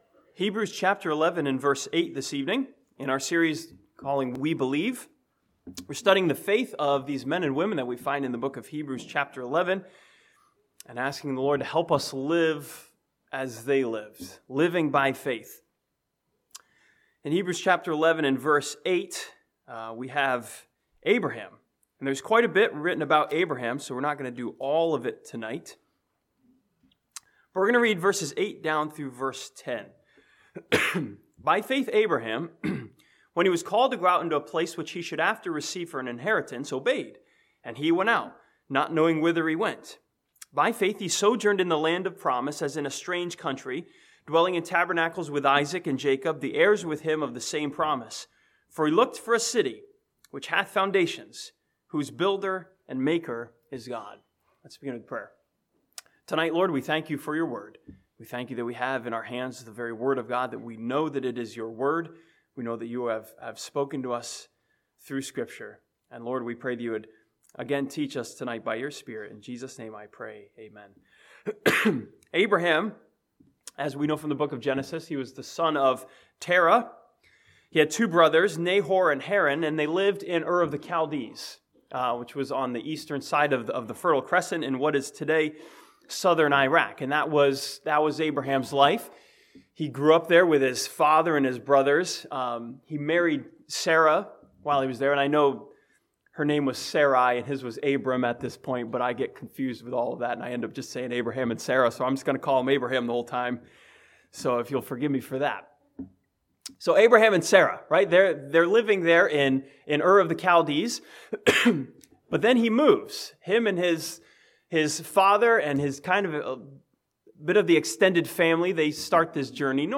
This sermon from Hebrews 11:8-10 challenges us to believe like Abraham that God has something better ahead of us.